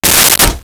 Open Medicine Cabinet Door 01
Open Medicine Cabinet Door 01.wav